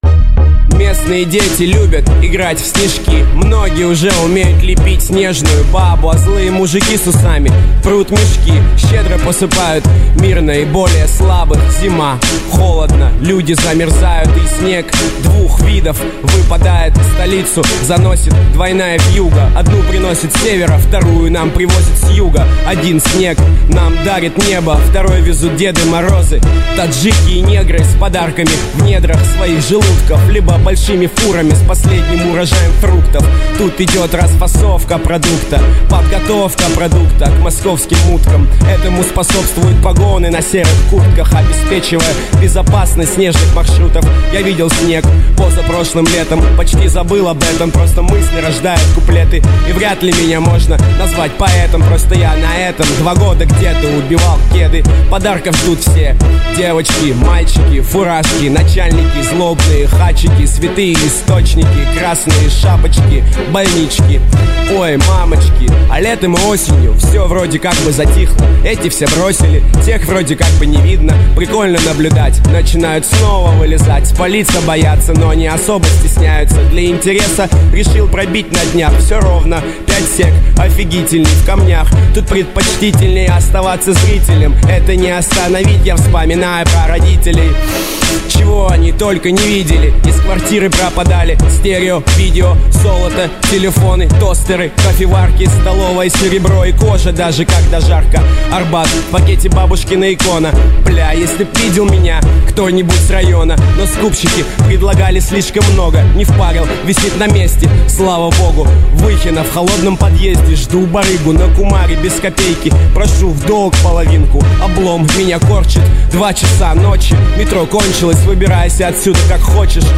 Главная » Русский реп, хип-хоп